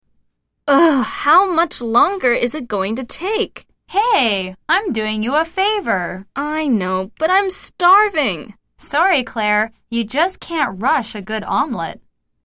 來聽老美怎麼？